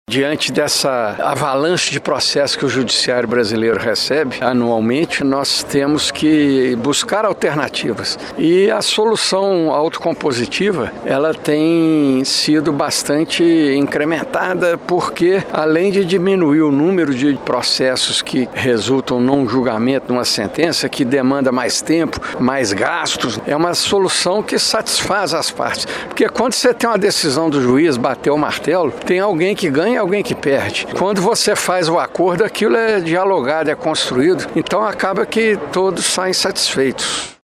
Falando ao Jornal da Manhã, o Desembargador Rogério Medeiros destacou a importância da iniciativa, que deverá fortalecer a política de práticas autocompositivas.